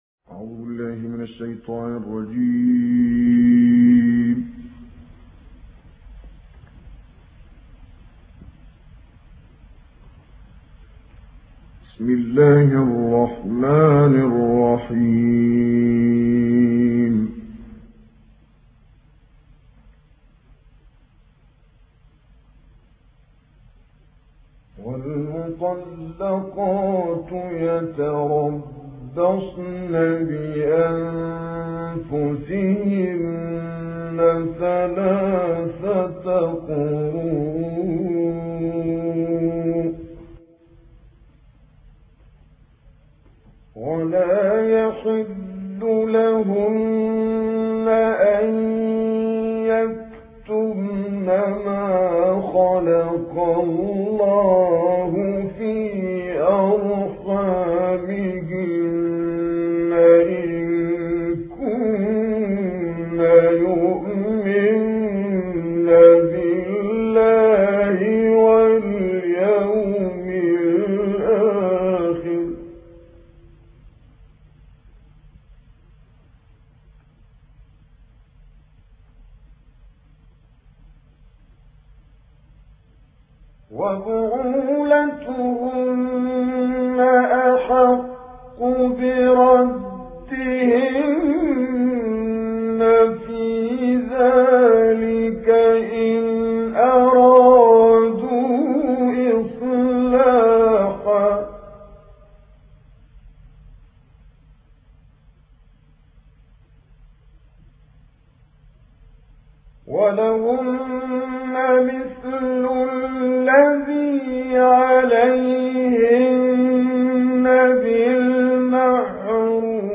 Quran recitations
Reciter Kamel Yousf El Behteemy